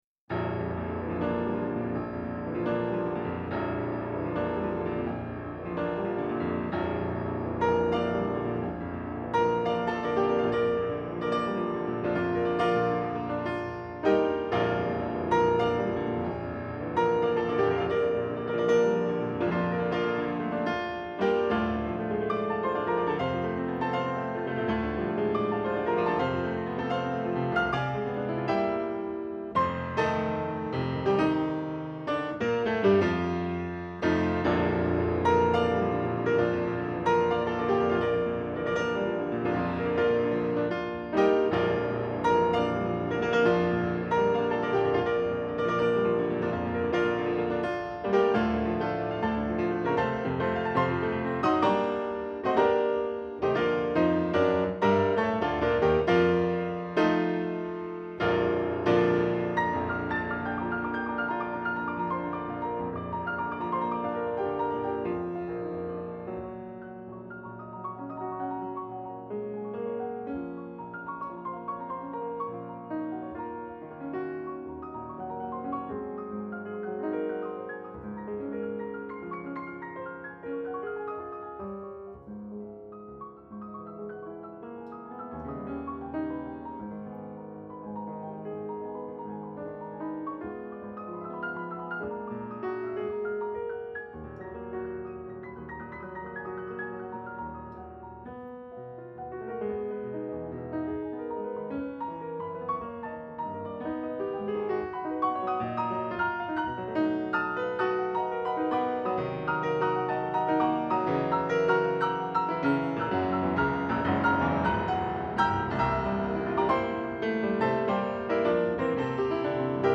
PISTES AUDIO PIANO :